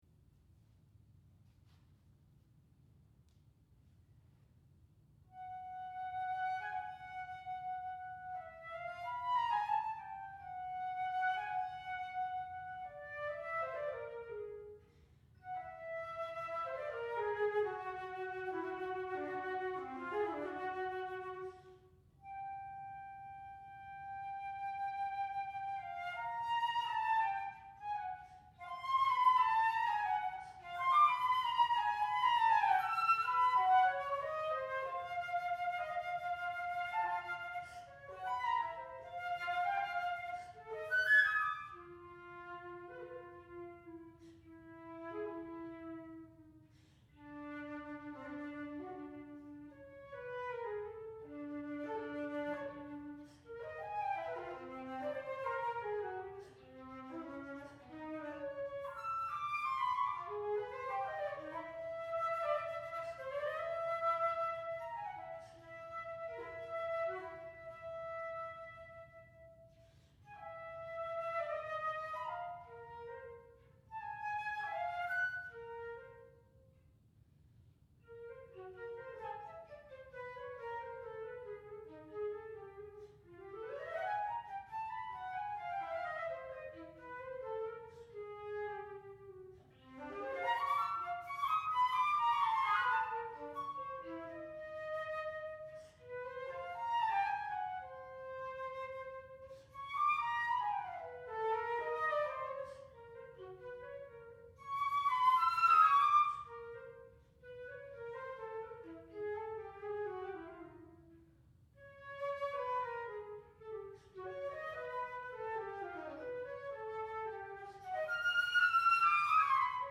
Solo Flute